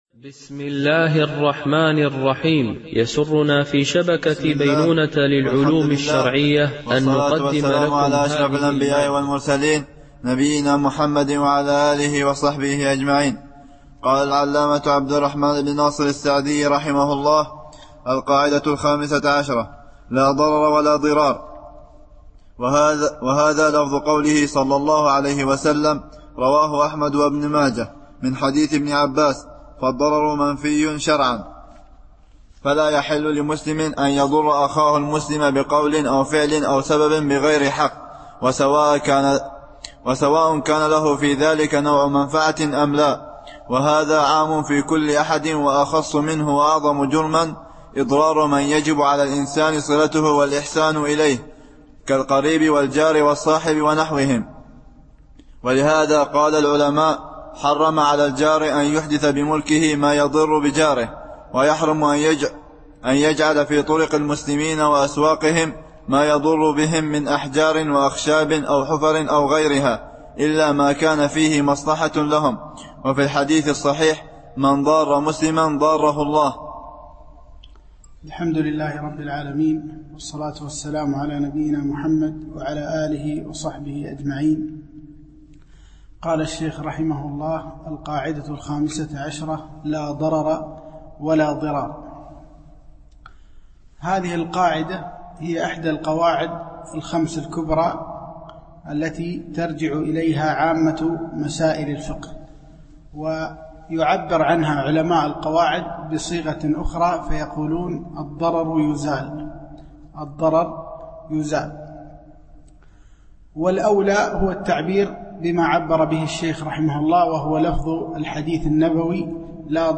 شرح القواعد والأصول الجامعة والفروق والتقاسيم البديعة النافعة - الدرس 10 ( القاعدة 15 ـ 17)